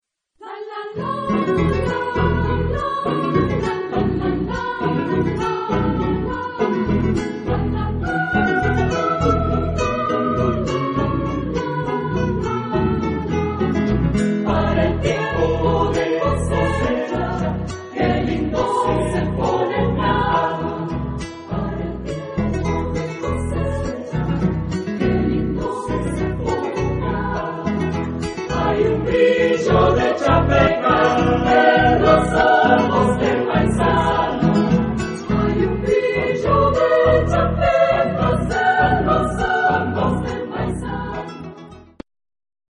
Carácter de la pieza : vivo
Tipo de formación coral: SAB  (3 voces Coro mixto )
Instrumentos: Piano (1)
Tonalidad : do mayor